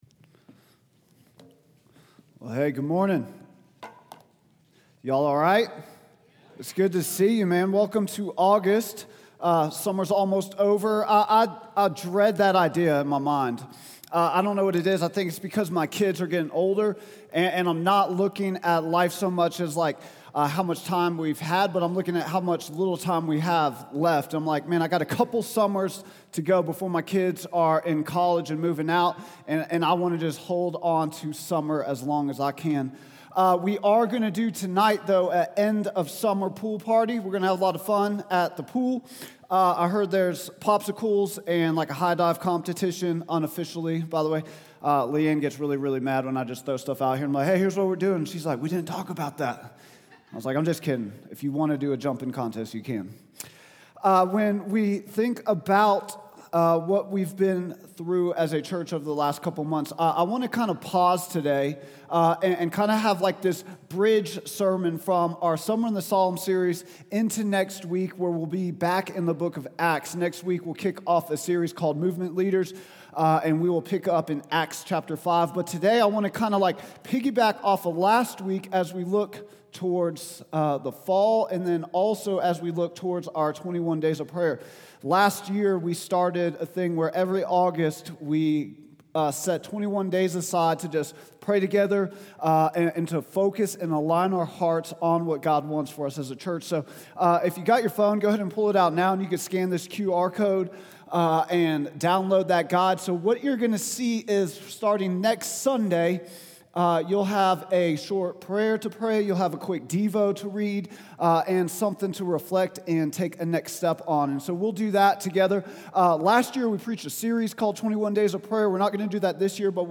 Vision & Values Meet Our Team Statement of Faith Sermons Contact Us Give We Joyfully Celebrate God's Grace August 3, 2025